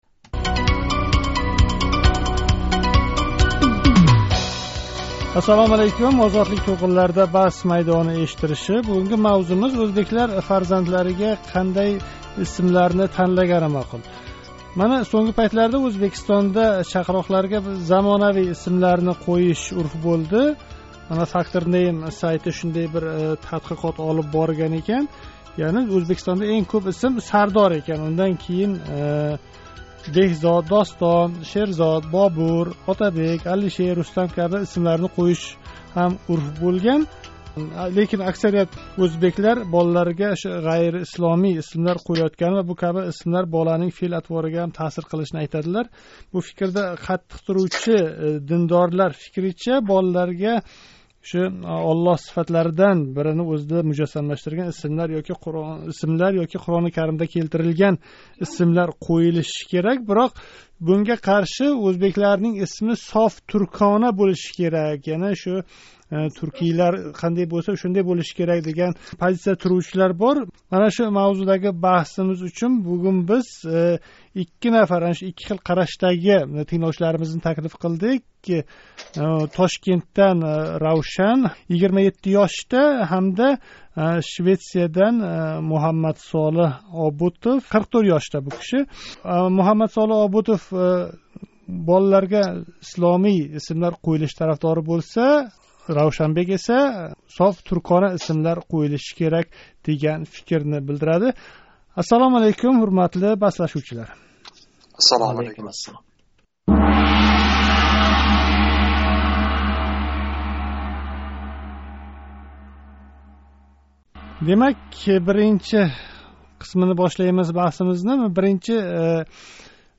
Озодликнинг навбатдаги Баҳс майдонида ўзбекларнинг фарзандларига исм танлаши ҳақида мунозара юритилди. Бу мунозарада фарзандларига исломий исмлар ёки замонавий исмларни қўйиш тарафдорлари баҳслашди.